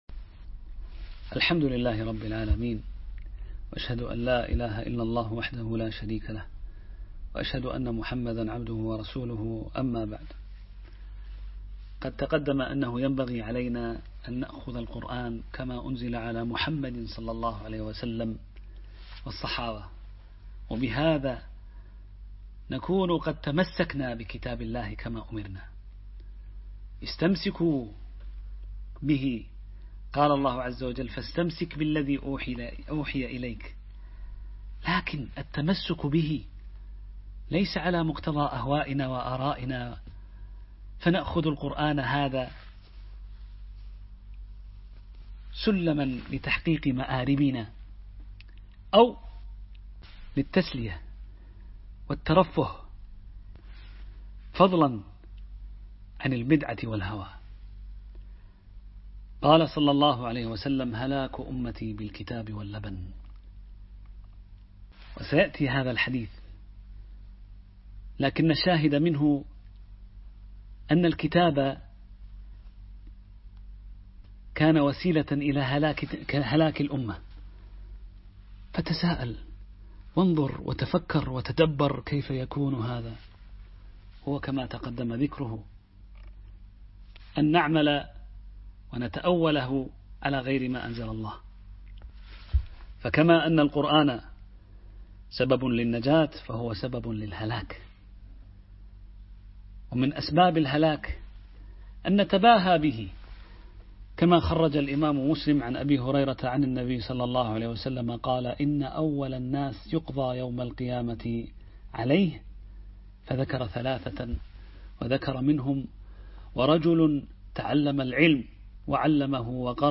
الدرس الثالث Album